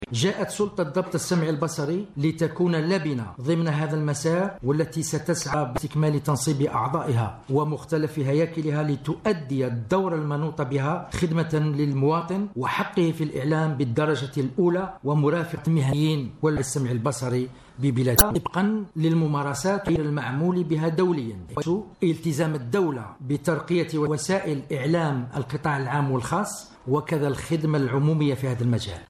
رئيس سلطة ضبط السمعي البصري ميلود شرفي يتحدث عن نشأة الهيئة رئيس ضبط السمعي البصري ميلود شرفي يتحدث عن مهام الهيئة رئيس سلطة ضبط السمعي البصري ميلود شرفي يتحدث عن دور الهيئة التي يرأسها في تنظيم القطاع